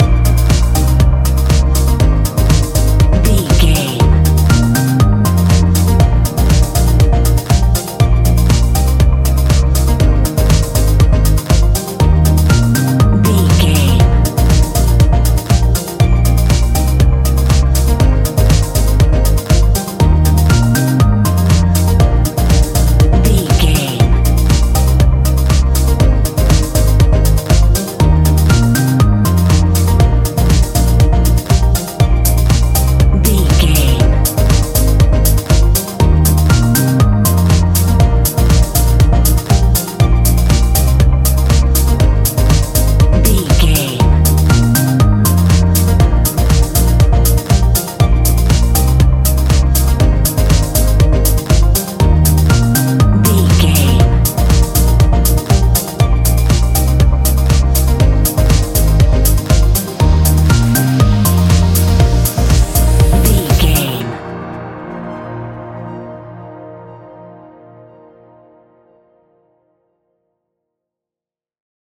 Ionian/Major
D♯
house
electro dance
synths
techno
trance